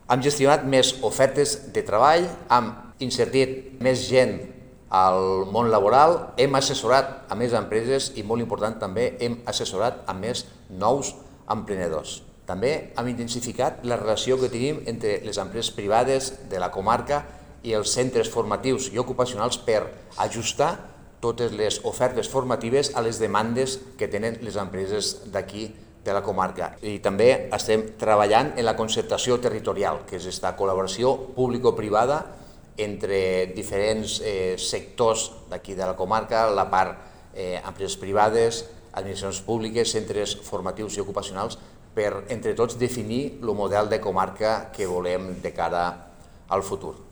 El president del Consell Comarcal del Baix Ebre, Toni Gilabert, ha destacat que s’ha intensificat l’activitat per millorar l’ocupabilitat de les persones i la competitivitat del teixit empresarial de la comarca